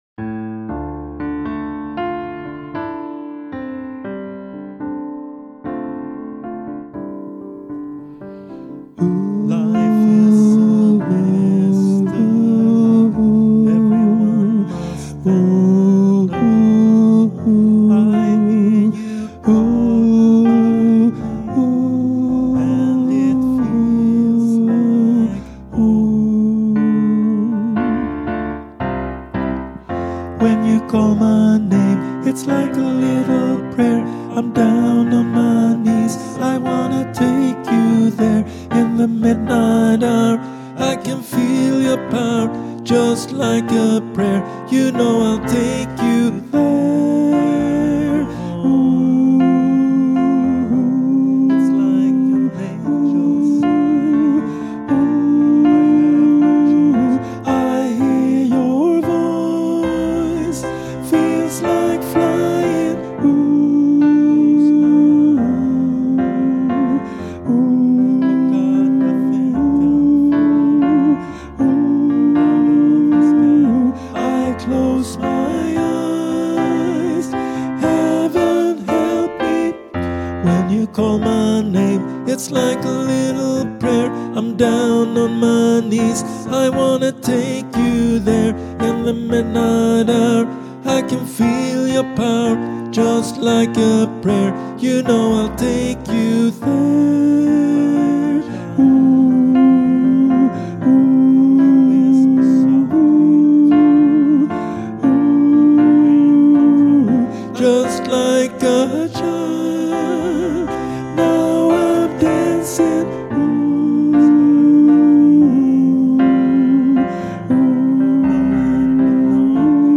LIke A Prayer 2025 - tenor.mp3